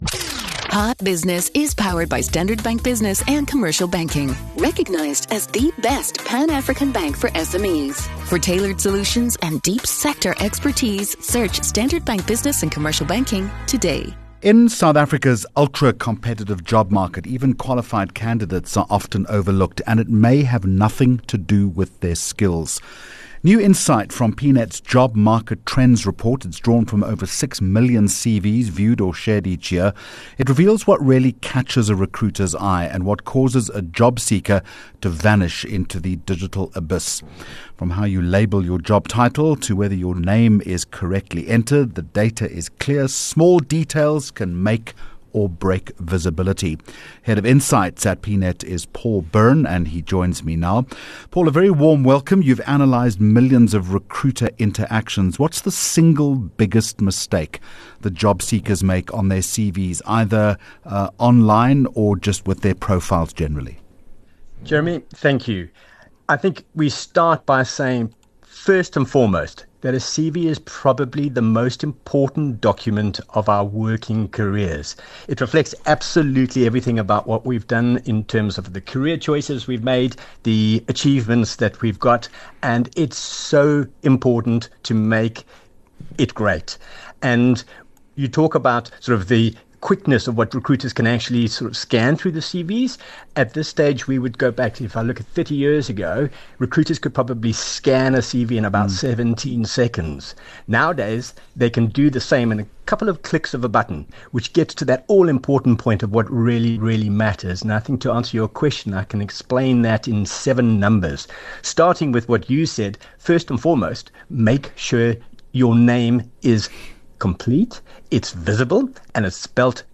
10 Jul Hot Business Interview